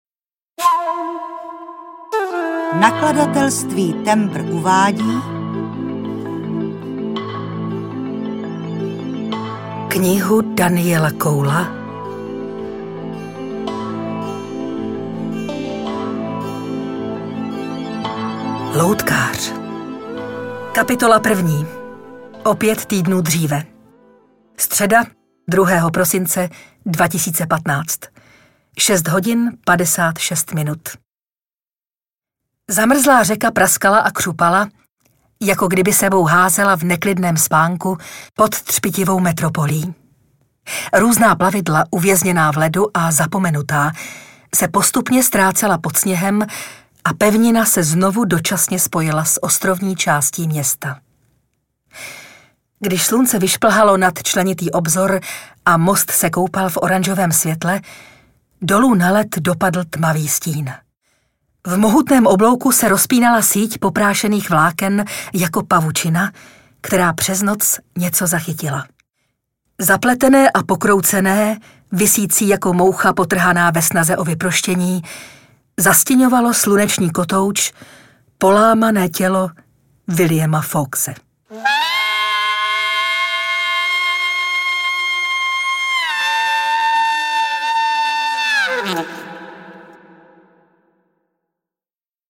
Loutkář audiokniha
Ukázka z knihy